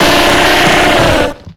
Cri de Gravalanch dans Pokémon X et Y.